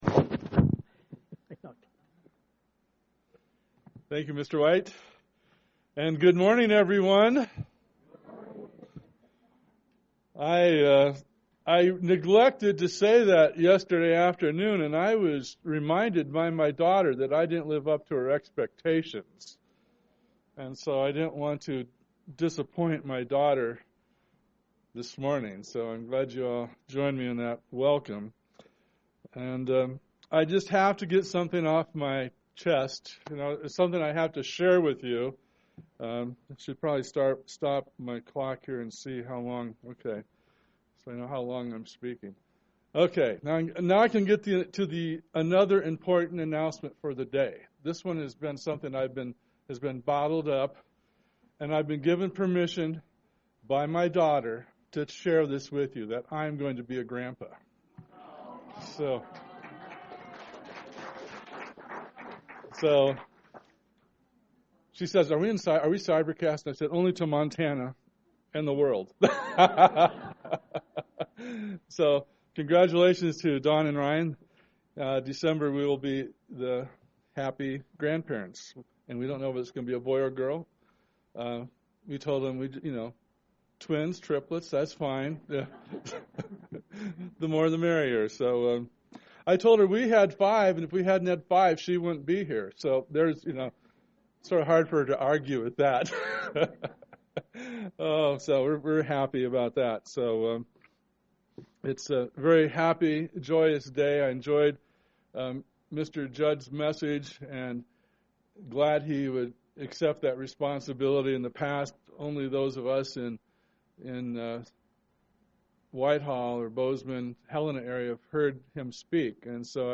The wise take action and do something with the truth and knowledge they've been given. This message was given on the Feast of Pentecost
This message was given on the Feast of Pentecost UCG Sermon Studying the bible?